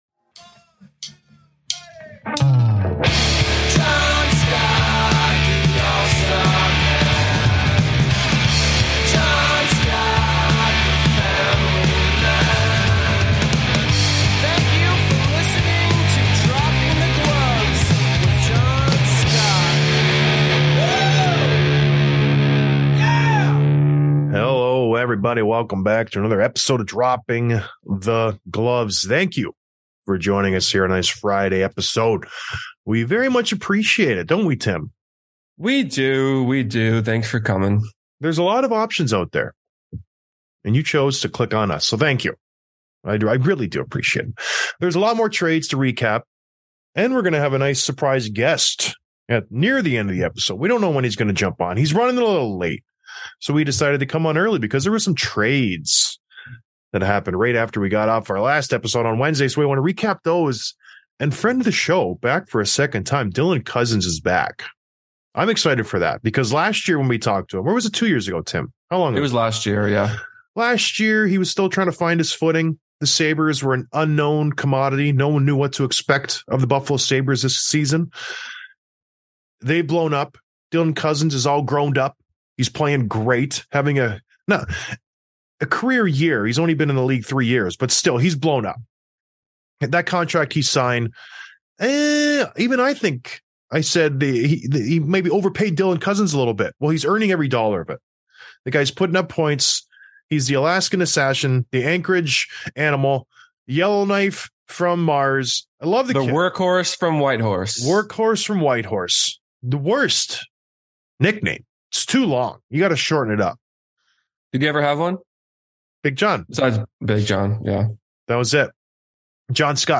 Trade Recaps and Interview with Dylan Cozens